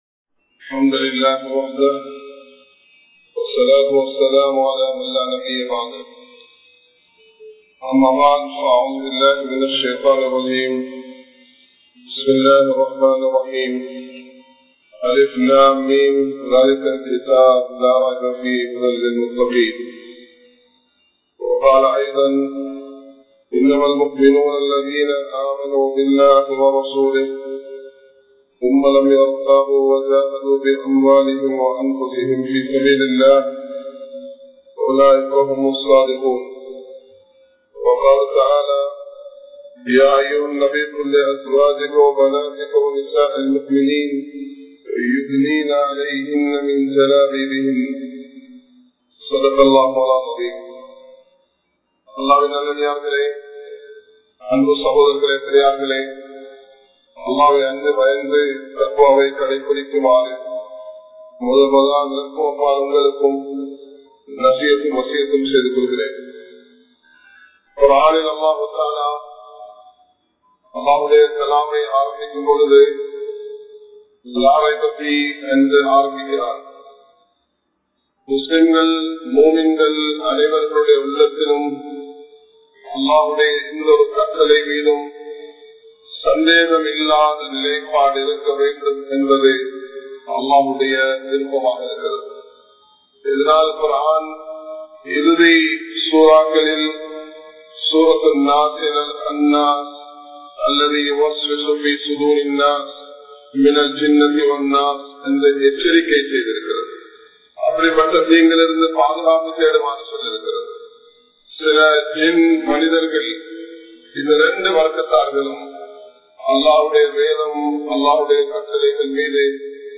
Hijab (ஹிஜாப்) | Audio Bayans | All Ceylon Muslim Youth Community | Addalaichenai
Kollupitty Jumua Masjith